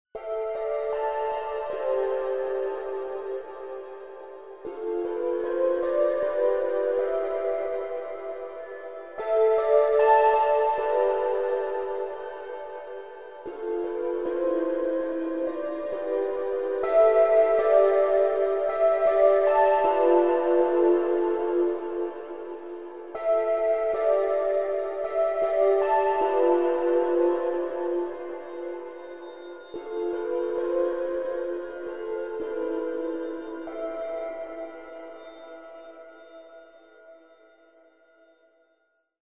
幻想的・神秘的な情景を表した音楽素材です。
意識が混濁する感じ